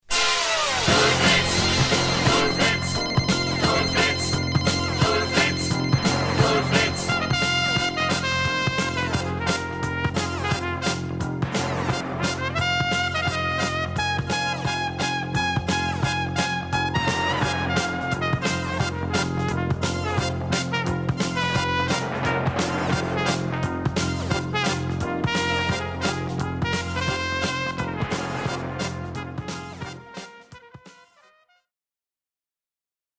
high note specialist